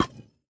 Minecraft Version Minecraft Version 1.21.5 Latest Release | Latest Snapshot 1.21.5 / assets / minecraft / sounds / block / decorated_pot / insert_fail5.ogg Compare With Compare With Latest Release | Latest Snapshot
insert_fail5.ogg